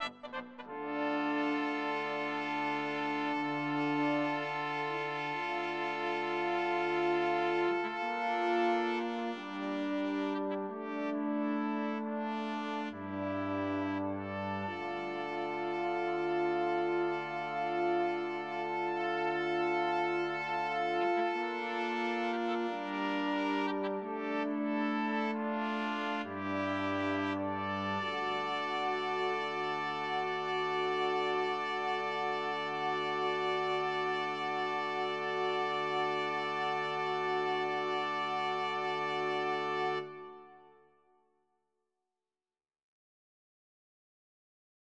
Ens. French Horn replaces original Coronet -- WAVE audio file